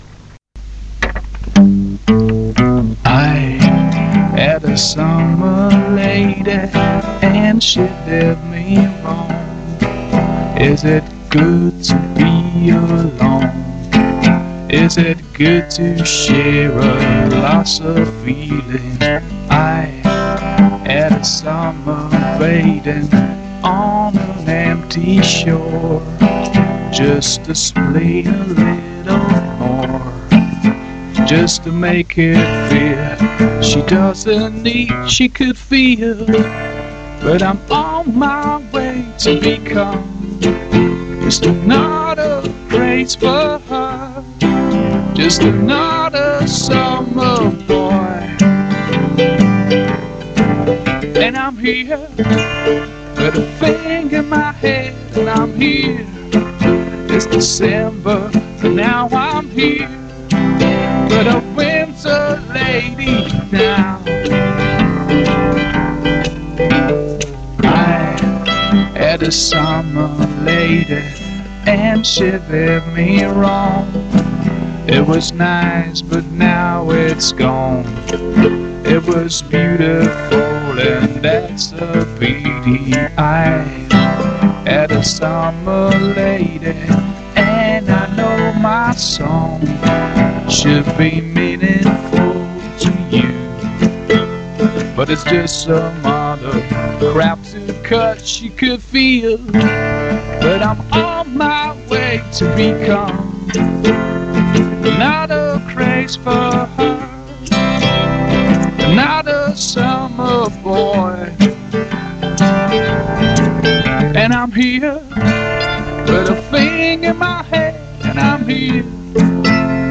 ecco un estratto live di quella trasmissione!